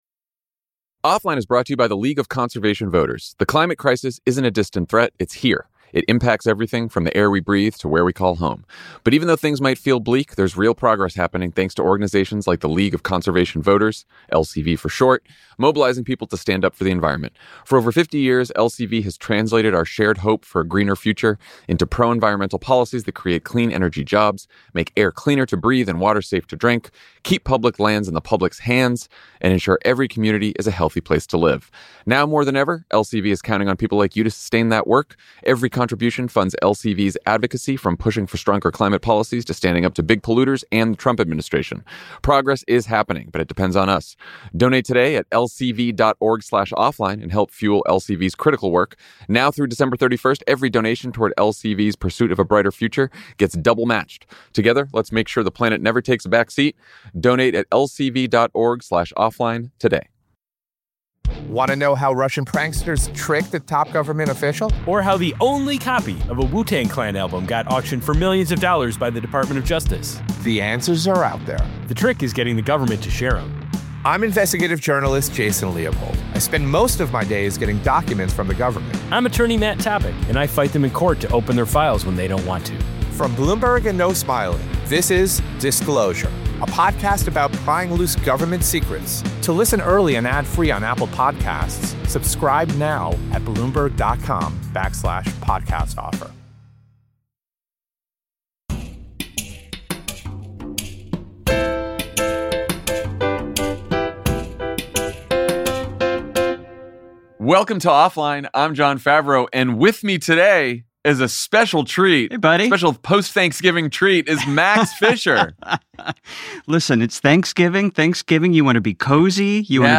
Max Fisher pays Offline a visit to take stock of the year in memes, conspiracy theories, and information siloes. He and Jon meet the ghosts of twitter fights past and future, compare notes on staying off their phones, and chat about what they’re watching right now…besides Zohran and Trump flirting.